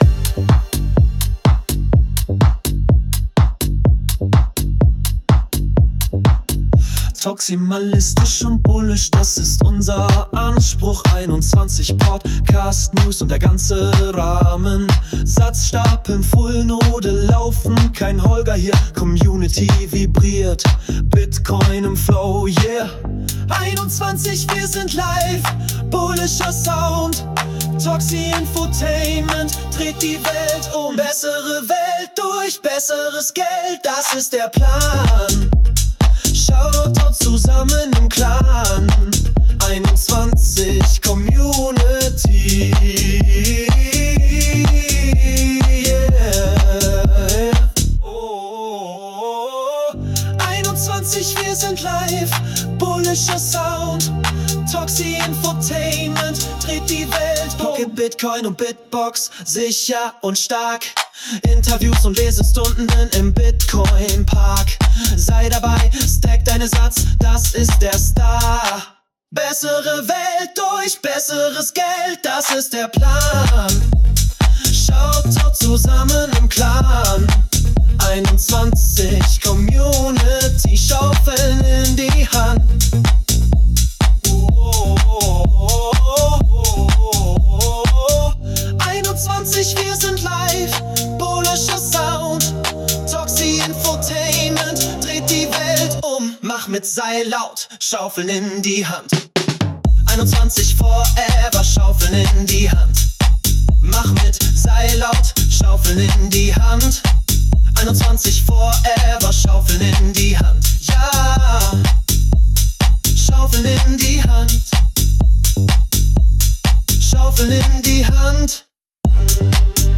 background-music.mp3